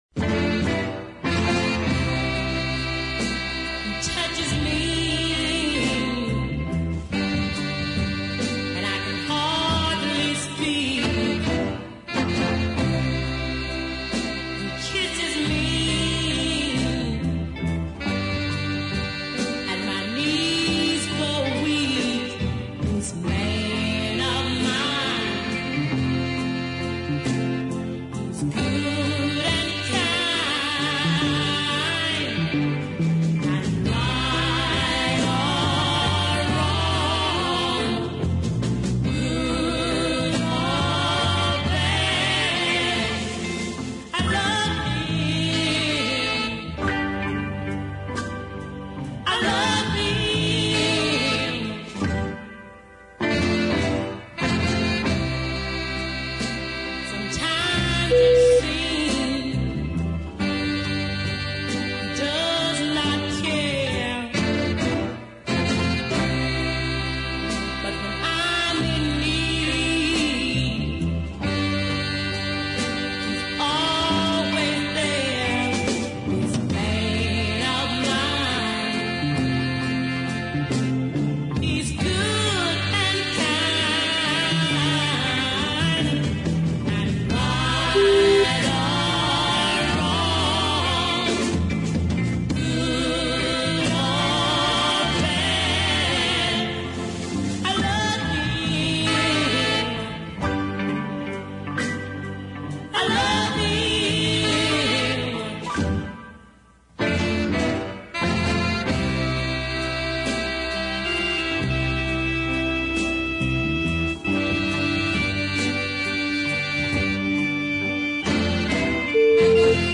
These Macon recordings
southern soul of genuine class from the Golden Age
multi tracked vocals give the song emphasis and bite